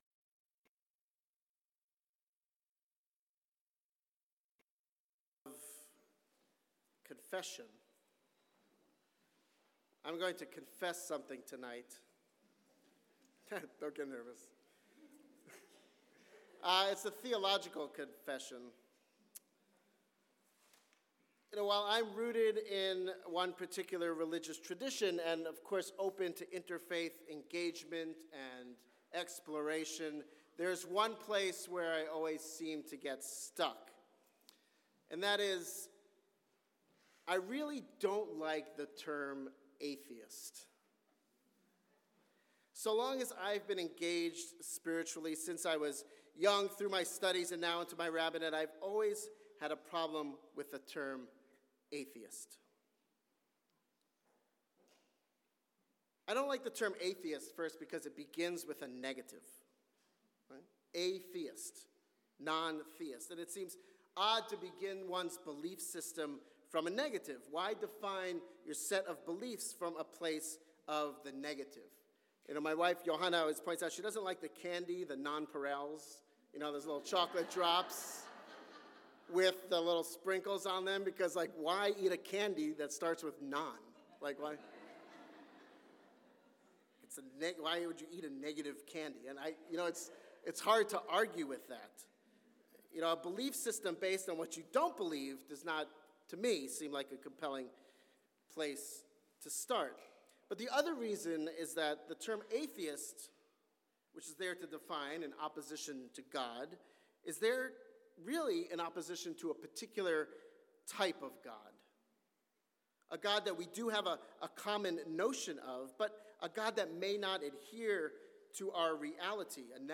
kol-nidre-sermon.mp3